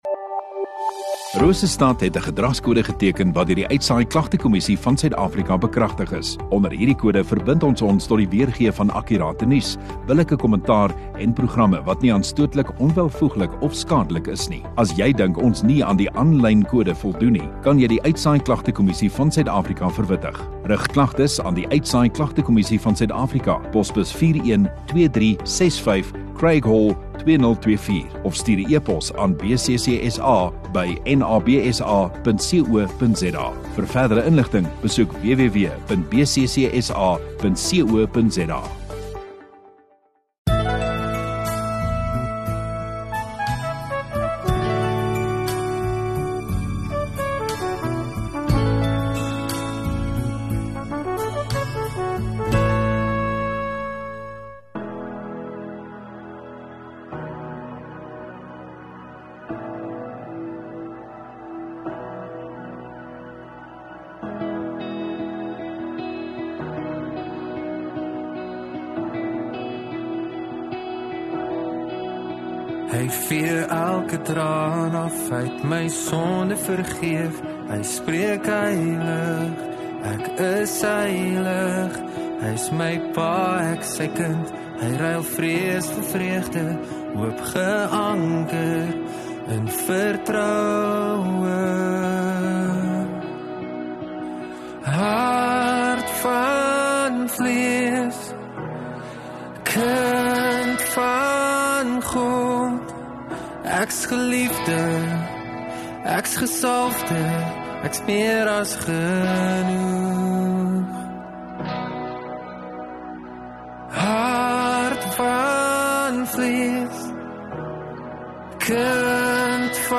5 Jan Sondagoggend Erediens